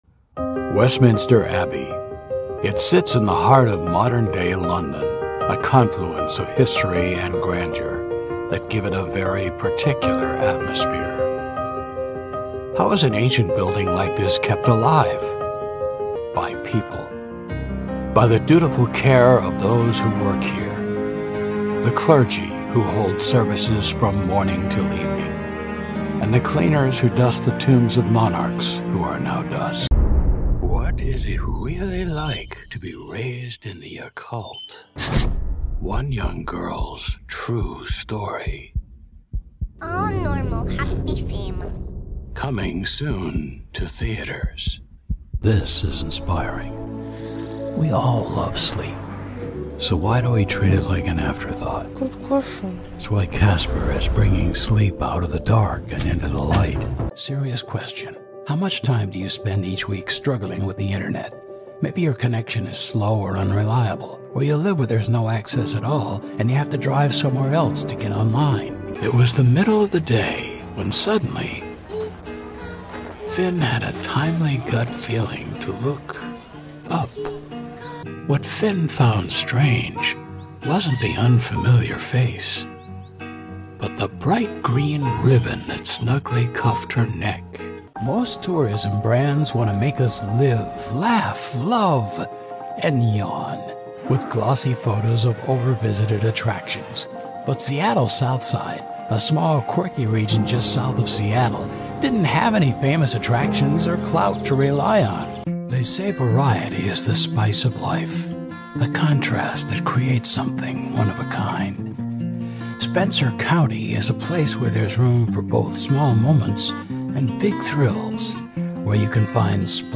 VO Demo
Irish, American South
Middle Aged
Senior